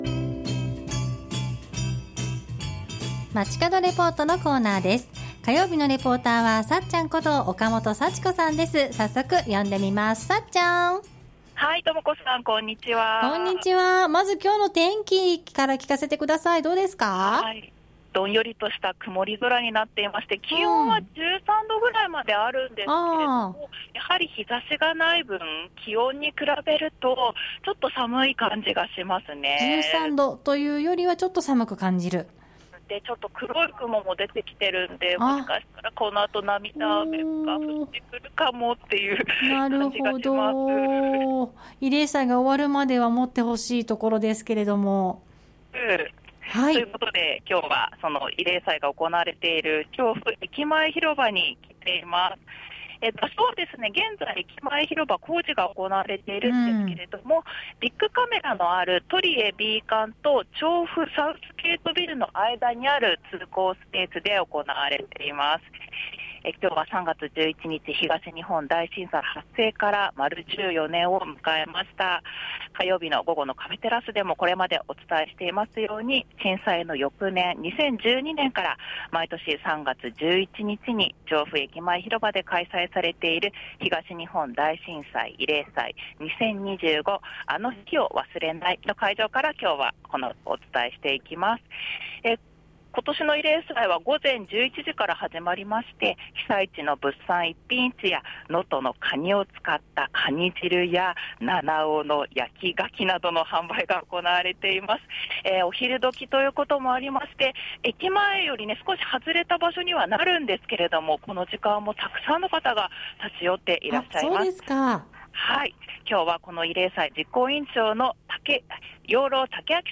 中継は調布駅前広場で開催された「東日本大震災慰霊祭2025～あの日を忘れない。～」の会場からお伝えしました。